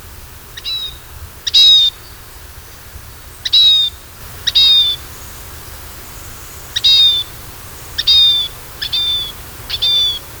Nutmeg Mannikin
Tamaño: 11 cm., peso: 12-15 g. El llamado de dos notas es más agudo que el del Diablito.